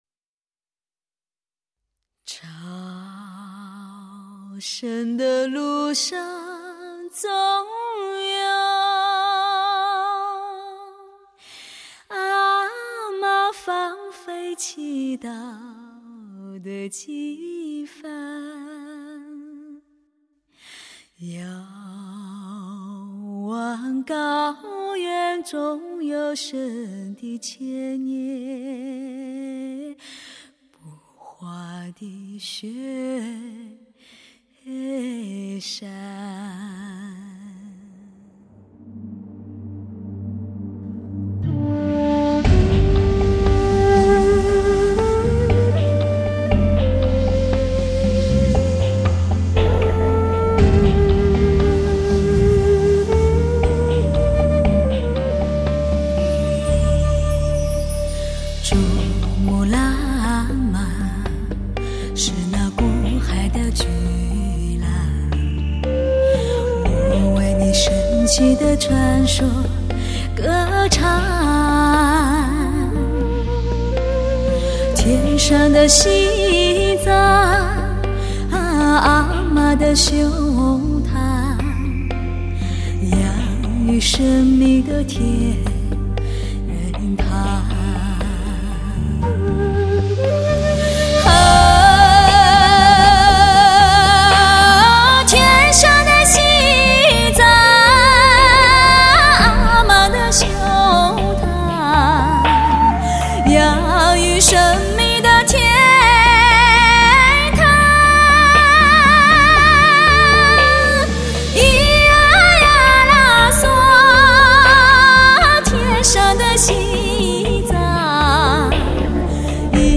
HD-MASTERING 采用1:1直刻无损高品质音源技术。
日本MASTERING技术处理，德国后期母带制作。